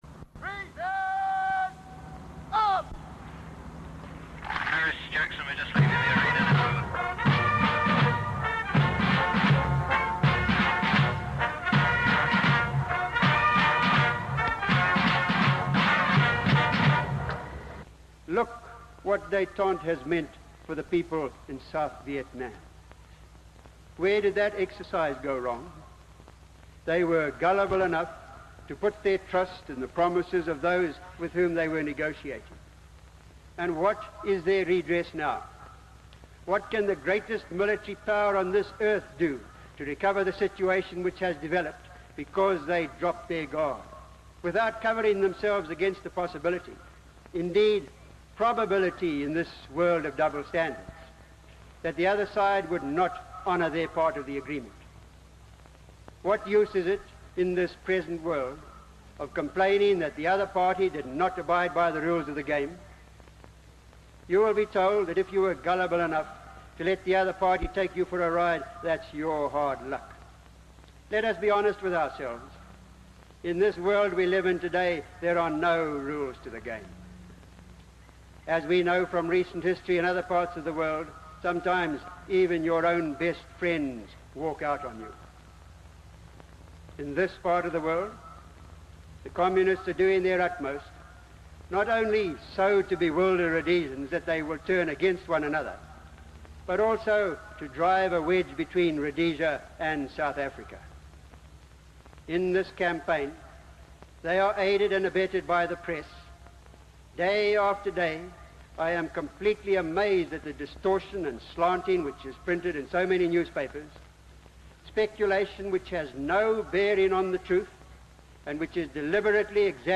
사이공 함락 당시 이안 스미스가 연설하는 모습. 그는 두 상황을 비교하며 로디지아 국민들에게 긴장 완화에 지나치게 의존하지 말라고 경고한다.